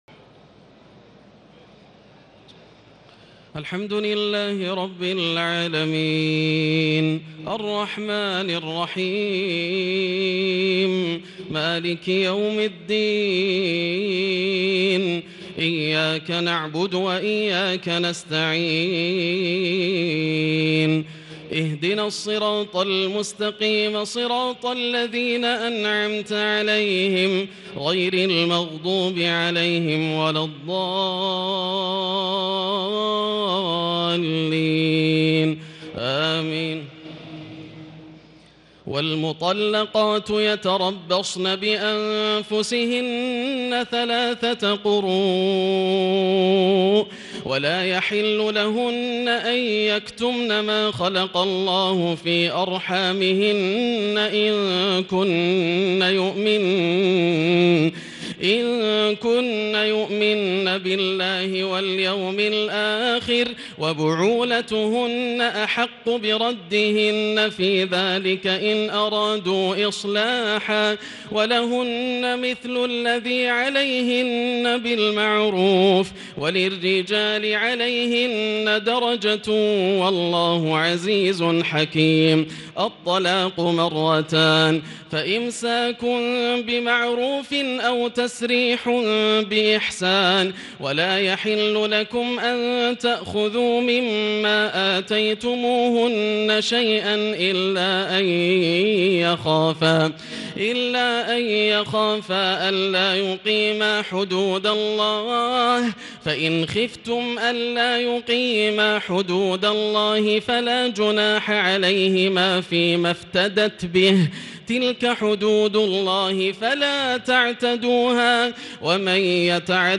تراويح الليلة الثانية رمضان 1440هـ من سورة البقرة (228-271) Taraweeh 2 st night Ramadan 1440H from Surah Al-Baqara > تراويح الحرم المكي عام 1440 🕋 > التراويح - تلاوات الحرمين